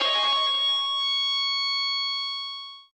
guitar_036.ogg